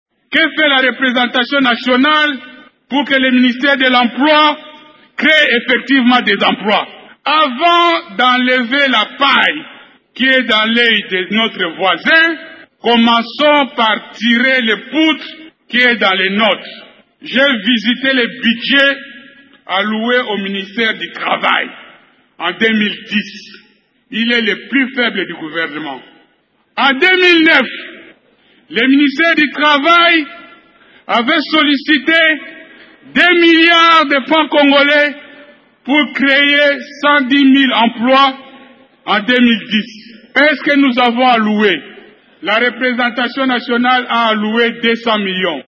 Il l’a dit dans cet extrait de ses propos à la plénière: